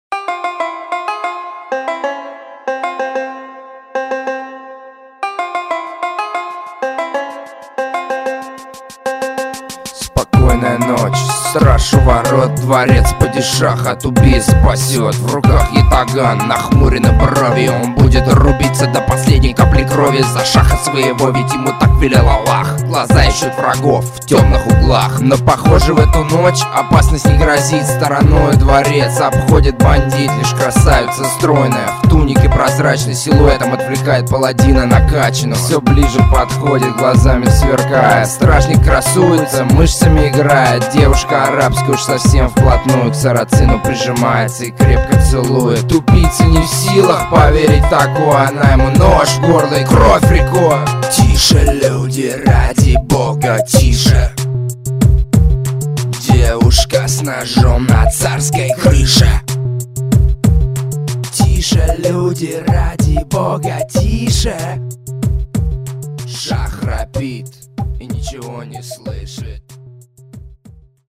Все делает сам - пишет слова, музыку, сам записывает и исполняет.
Я занимаюсь русским рэпом.